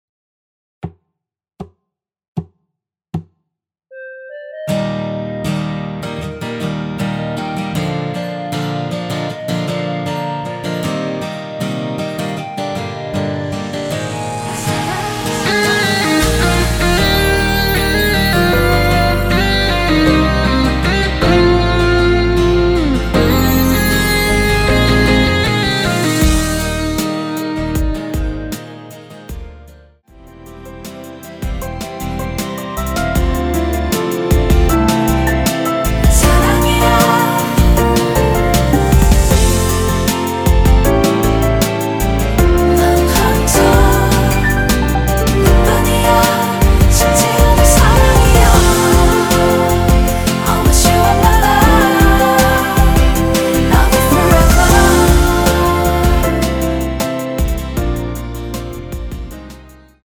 전주 없이 시작하는 곡이라서 노래하기 편하게 카운트 4박 넣었습니다.(미리듣기 확인)
원키에서(-1)내린 멜로디와 코러스 포함된 MR입니다.(미리듣기 확인)
Eb
앞부분30초, 뒷부분30초씩 편집해서 올려 드리고 있습니다.